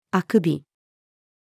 あくび-female.mp3